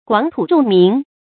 廣土眾民 注音： ㄍㄨㄤˇ ㄊㄨˇ ㄓㄨㄙˋ ㄇㄧㄣˊ 讀音讀法： 意思解釋： 土地廣闊，人民眾多。